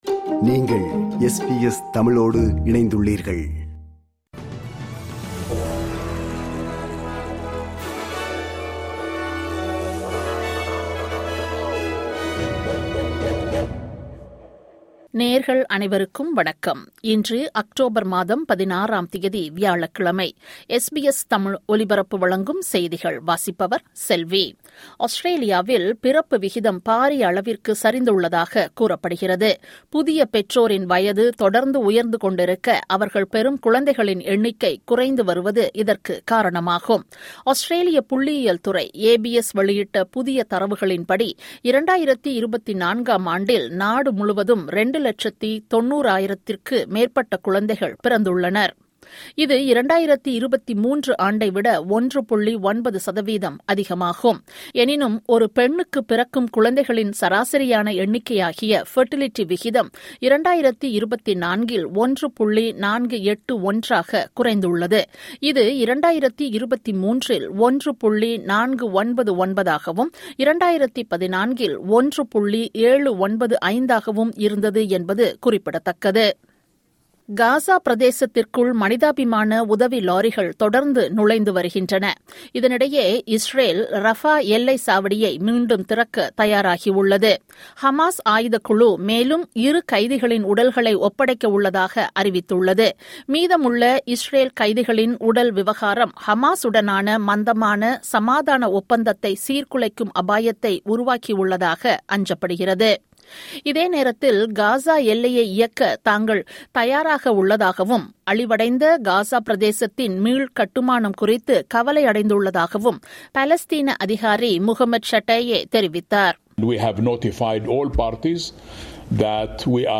இன்றைய செய்திகள்: 16 அக்டோபர் 2025 வியாழக்கிழமை